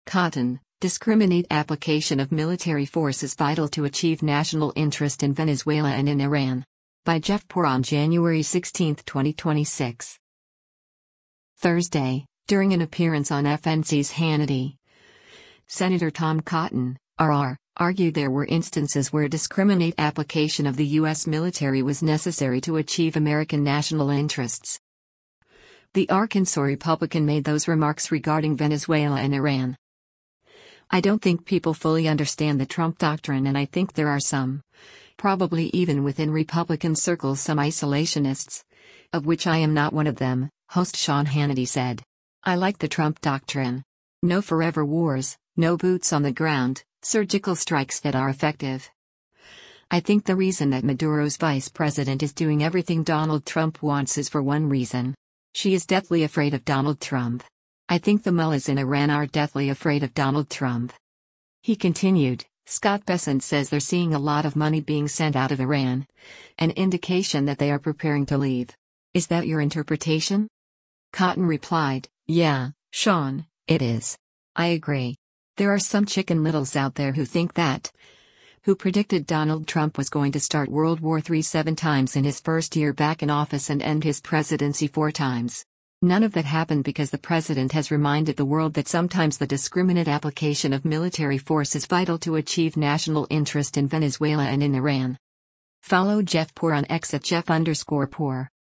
Thursday, during an appearance on FNC’s “Hannity,” Sen. Tom Cotton (R-AR) argued there were instances where “discriminate application” of the U.S. military was necessary to achieve American national interests.